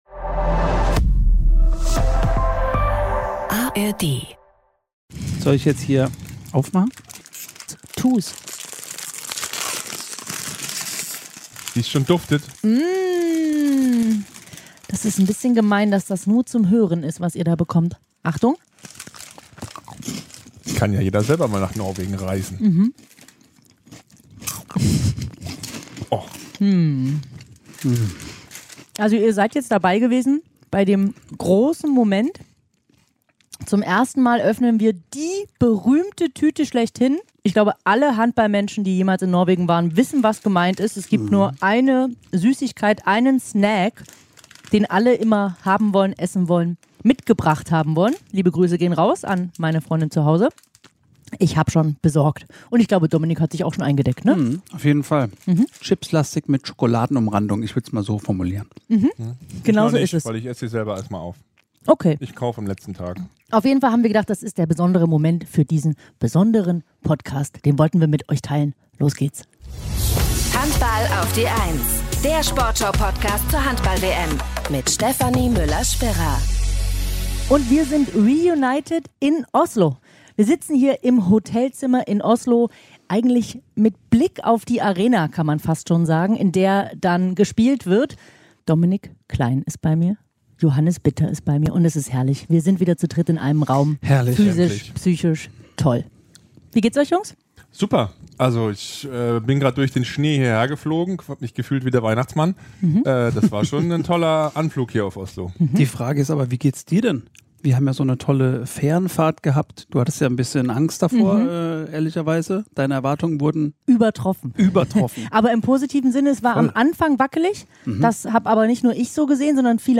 Reunion in Oslo.